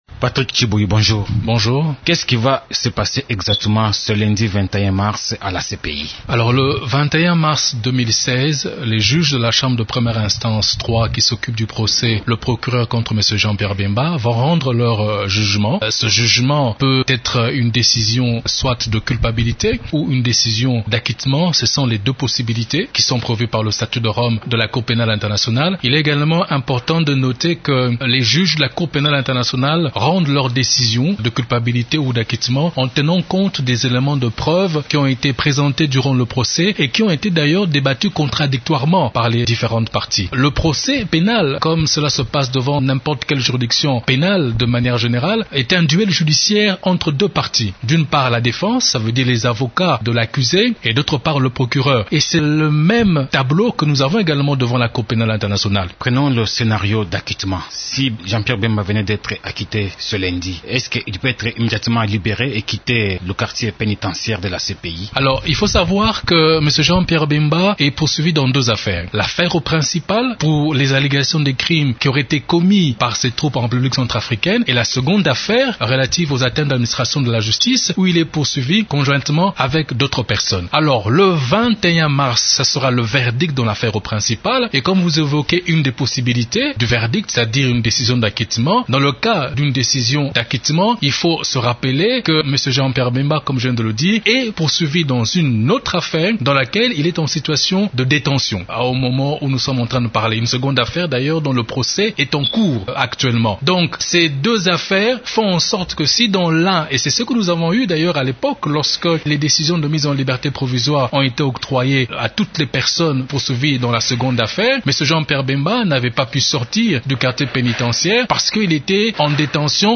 Il est l’invité de Radio Okapi.   La CPI doit rendre son verdict le 21 mars prochain dans le cadre du procès le procureur contre Jean-Pierre Bemba, concernant les crimes qu’auraient commis ses anciens soldats en Centrafrique entre 2002 et 2003.